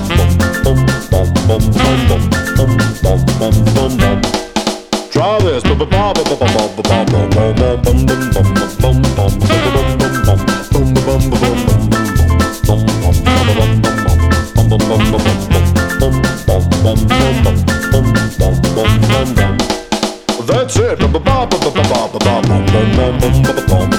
For Duet Rock 'n' Roll 2:28 Buy £1.50